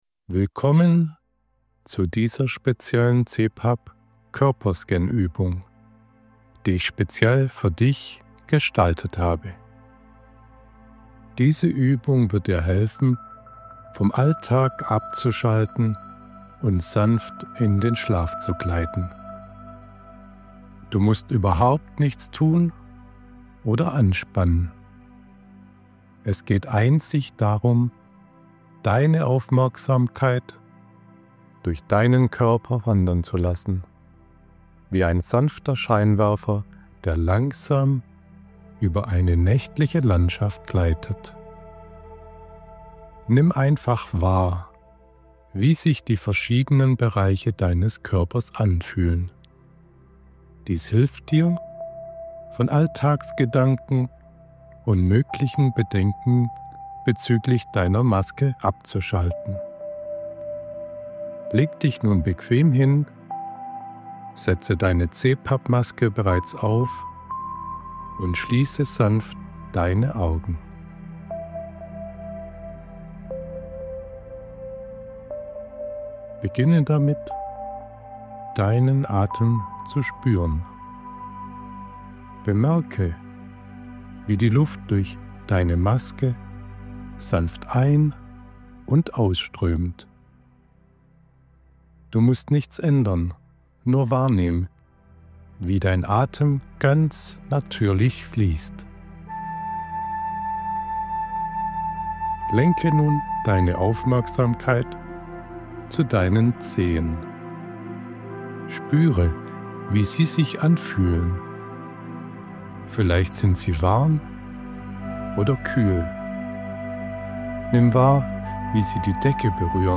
Körperscanübung
Koerperscanuebung-fertig.mp3